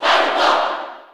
Falco_Cheer_JP_SSB4.ogg